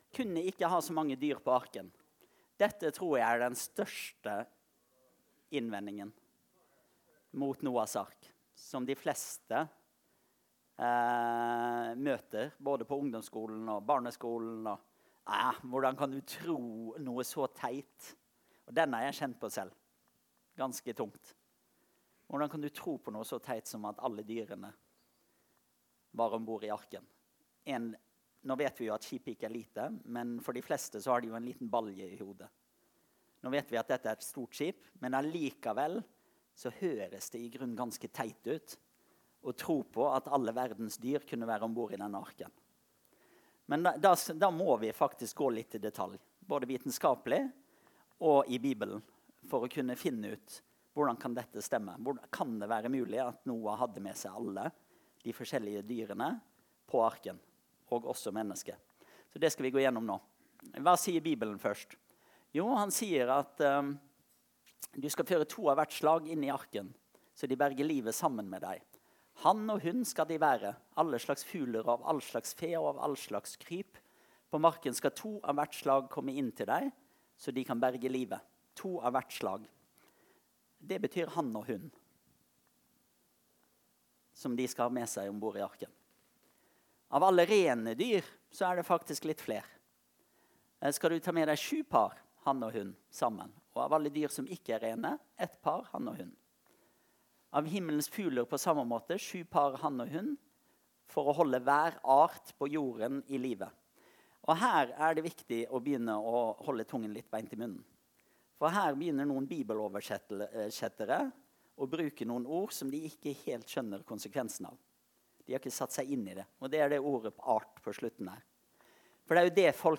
Møte: Seminar